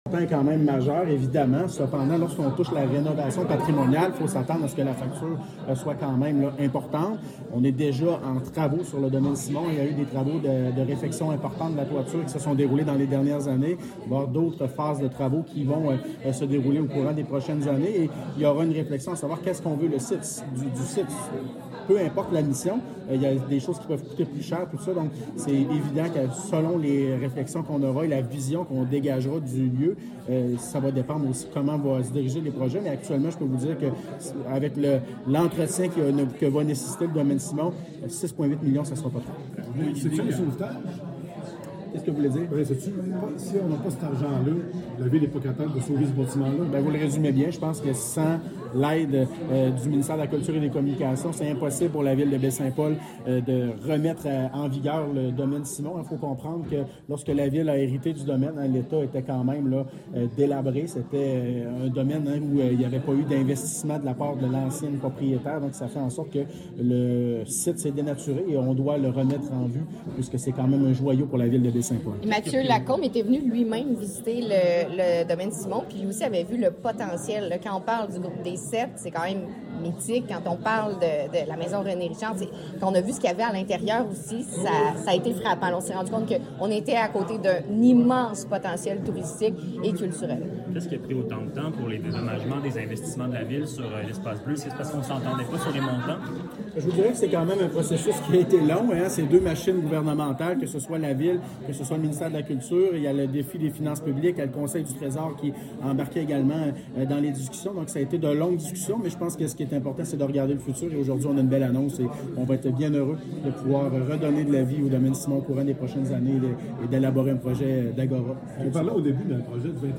L’annonce a eu lieu au Carrefour culturel Paul-Médéric.
Extrait mêlé de presse de Kariane Bourassa, députée caquiste de Charlevoix–Côte-de-Beaupré, et le maire de Baie-Saint-Paul, M. Michaël Pilote.